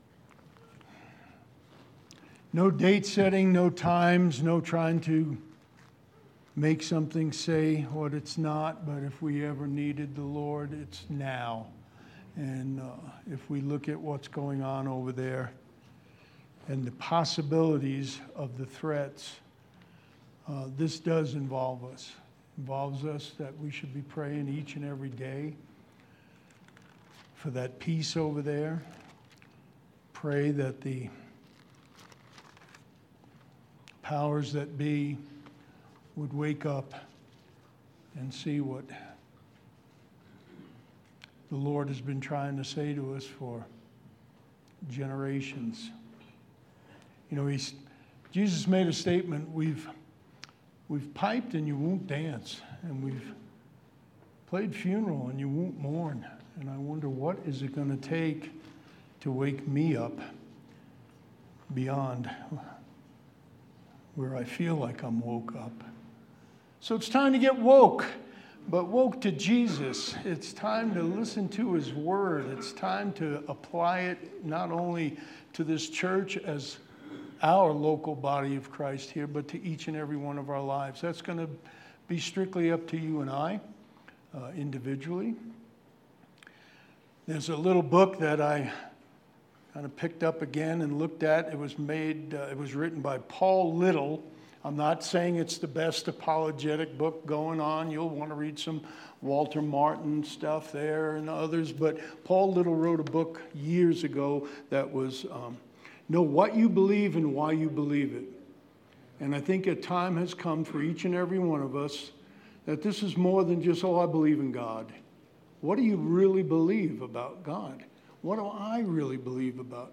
February 27, 2022 Sermon